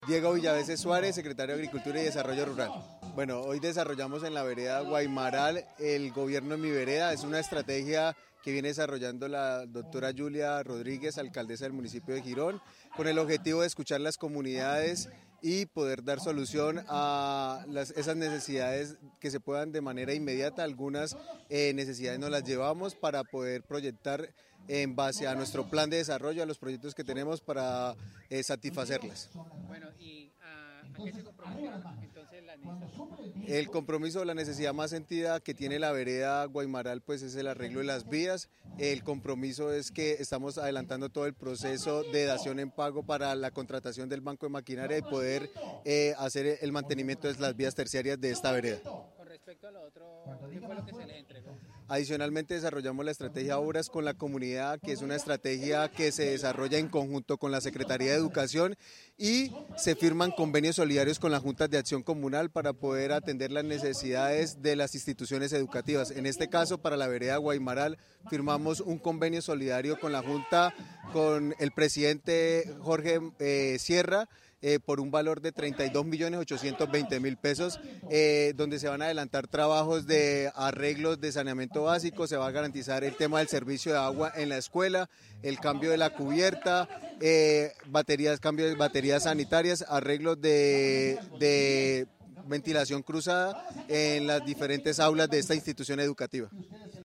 Diego Villaveces Suárez, Secretario de Agricultura.mp3